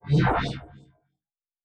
pgs/Assets/Audio/Sci-Fi Sounds/Movement/Synth Whoosh 5_3.wav at master
Synth Whoosh 5_3.wav